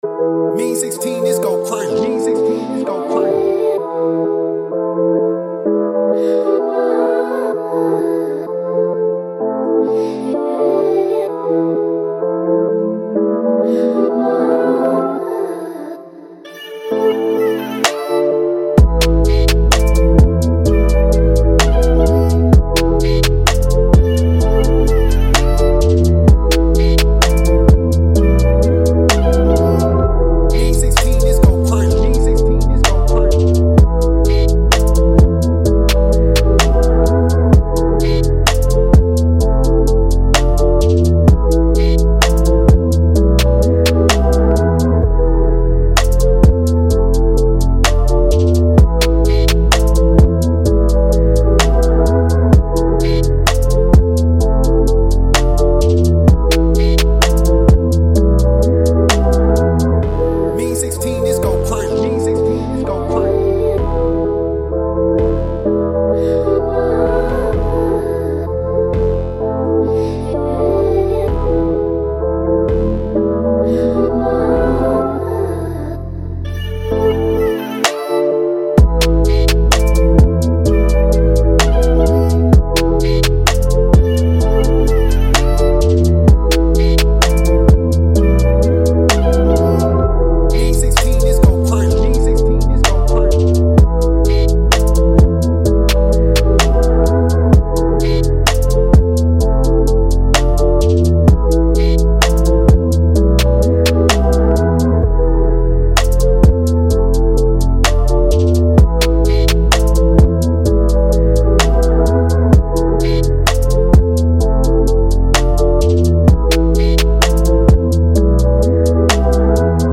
A-Min 128-BPM